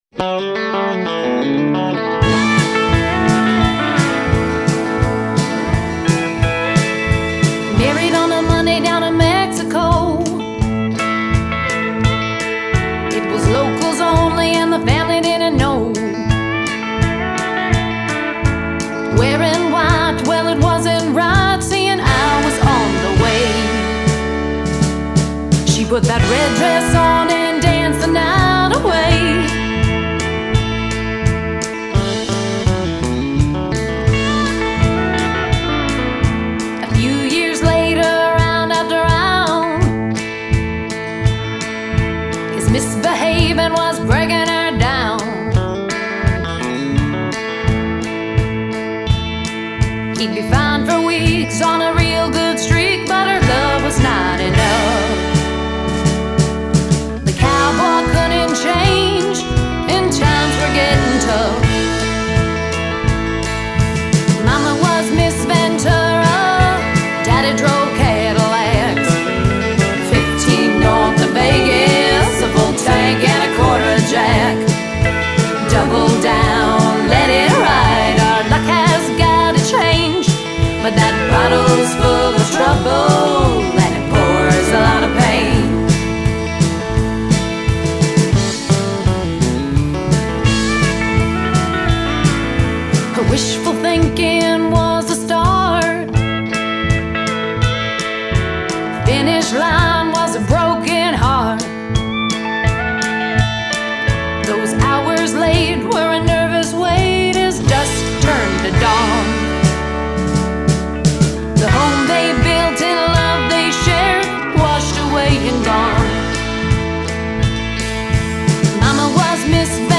Pedal Steel